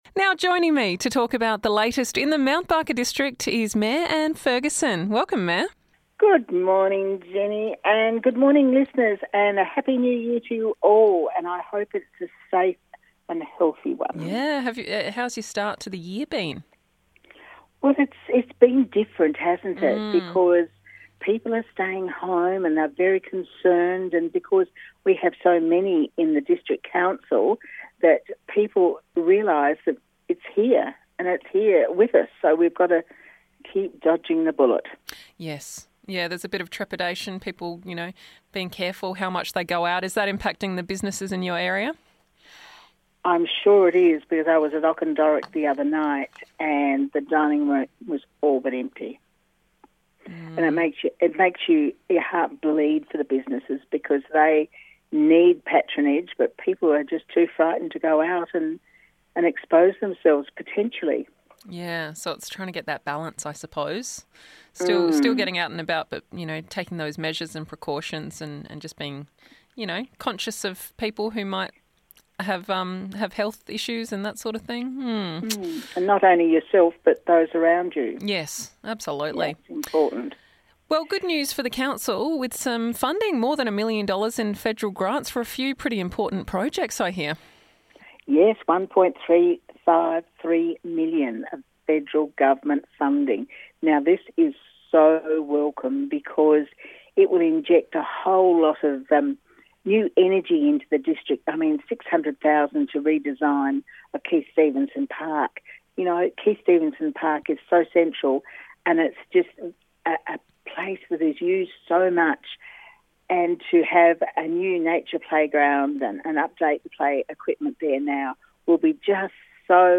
Mayor Ann Ferguson joins